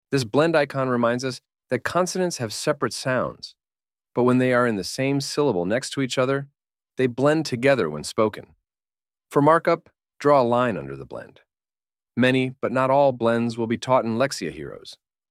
blend-icon-lesson-AI.mp3